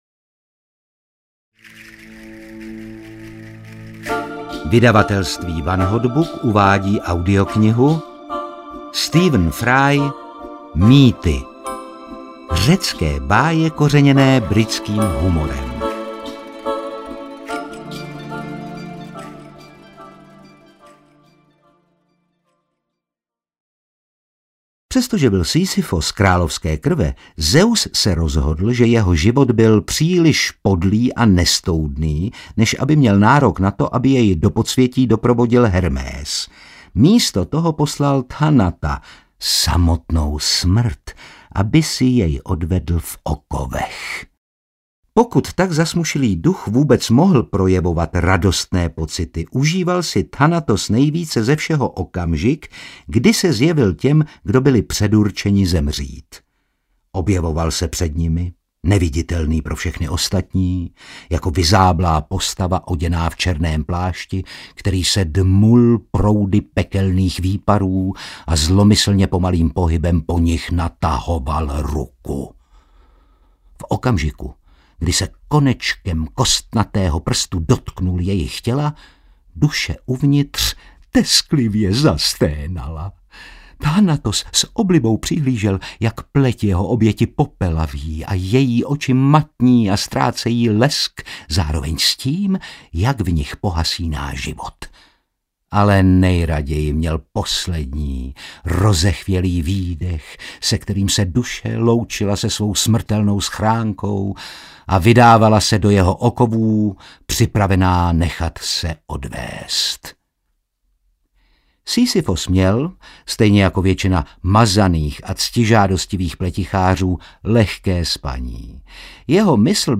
Mýty audiokniha
Ukázka z knihy